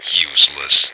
flak_m/sounds/male2/int/M2Useless.ogg at efc08c3d1633b478afbfe5c214bbab017949b51b